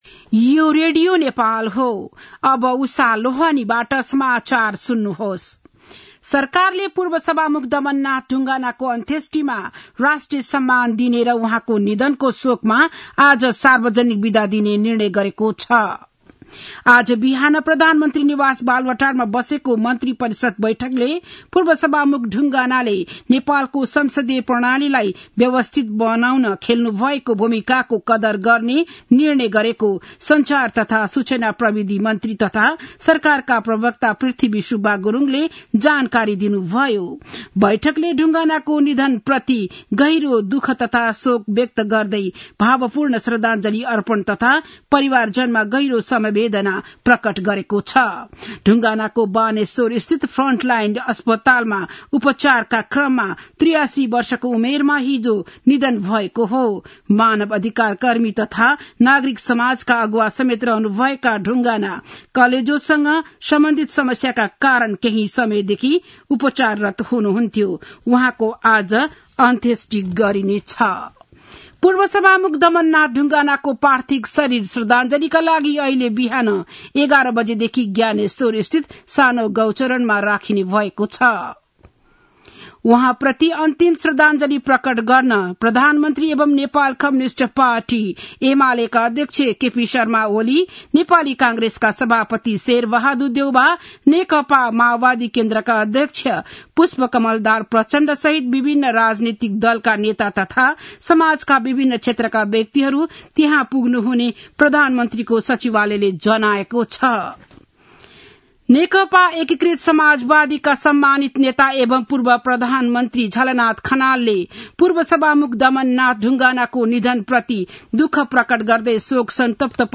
बिहान ११ बजेको नेपाली समाचार : ४ मंसिर , २०८१
11-am-nepali-news-1-3.mp3